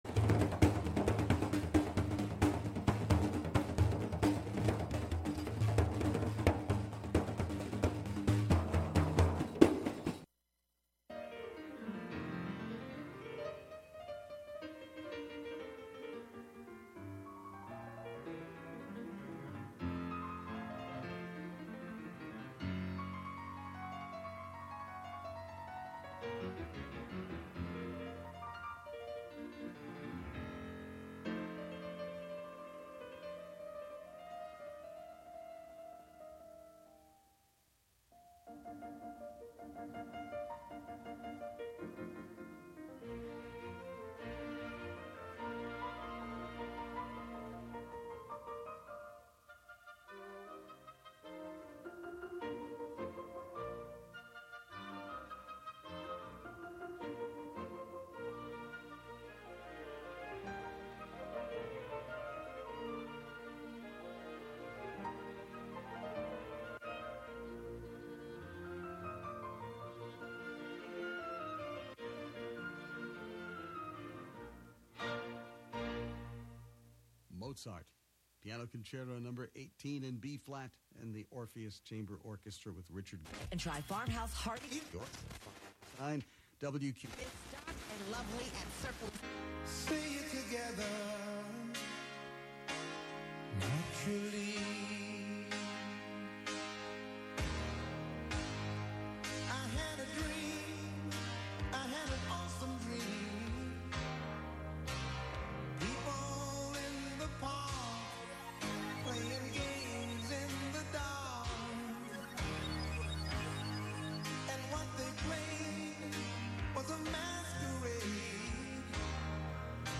Live from Brooklyn, NY